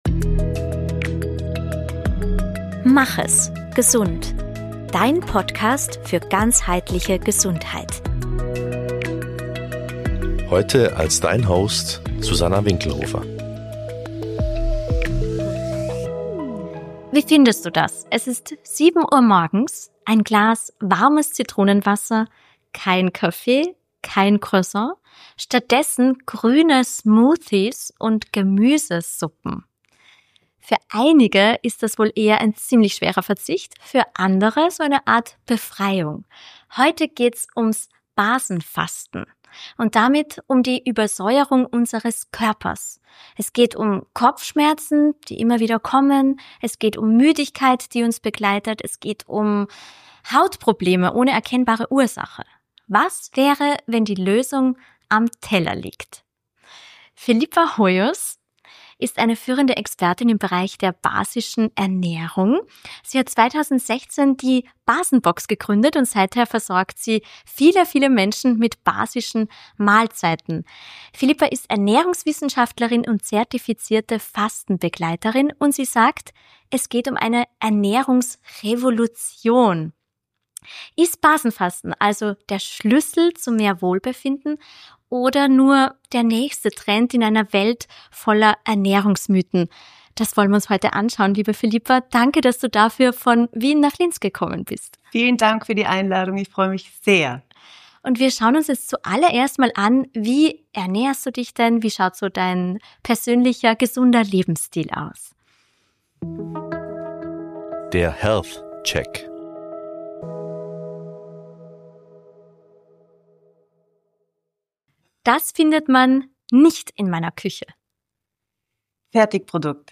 Ein Gespräch über Säuren, Basen – und darüber, wie man den Körper wieder ins Gleichgewicht bringt.